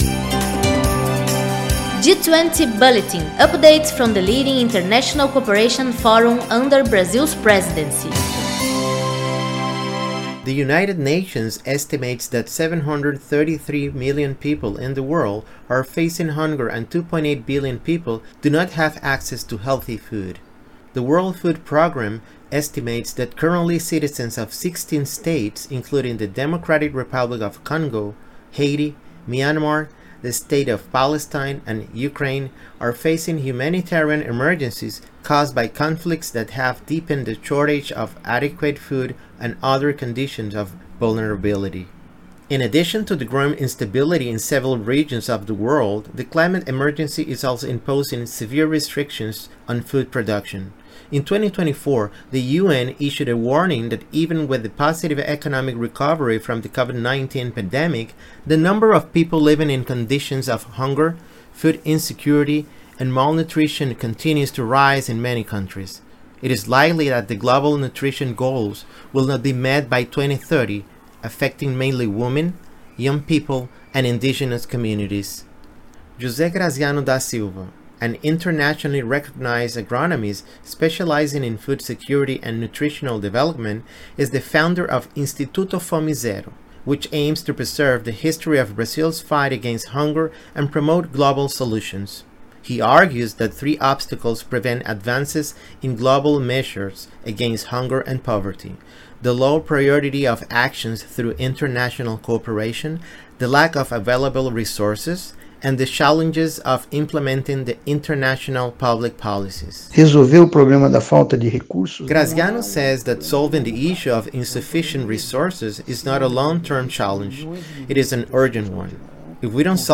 At the opening of the week of debates on tackling climate change at the G20, João Paulo Capobianco, Executive Secretary of the Ministry of the Environment, emphasized the importance of the climate agenda and called on developed countries to increase financing to tackle the climate crisis. Listen to the report and learn more.